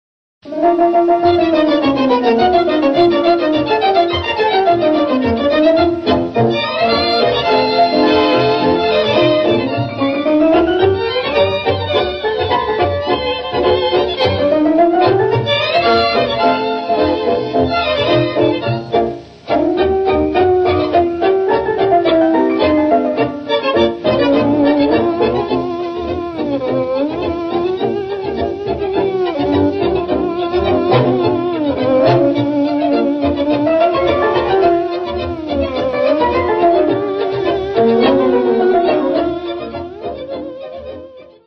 Einige gern gehörte/ getanzte Milonga-Stücke